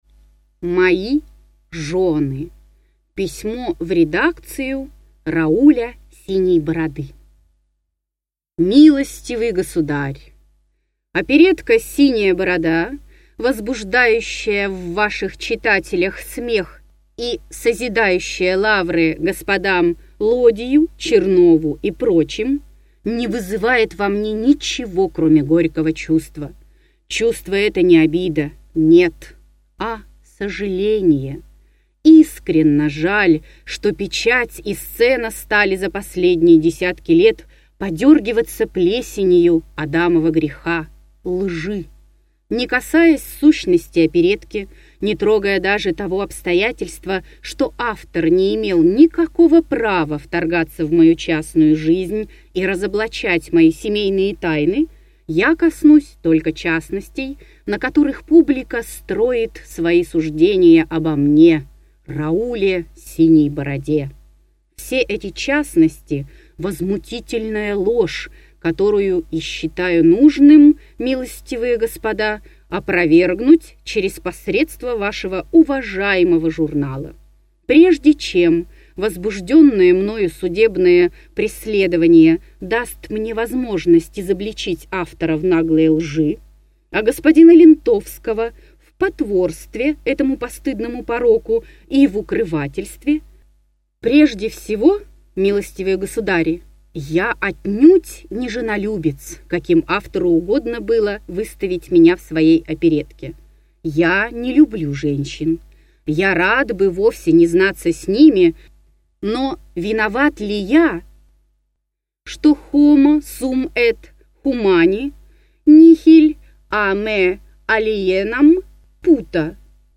Аудиокнига Рассказы, юморески 1884 – 1885 г.г. Том 11 | Библиотека аудиокниг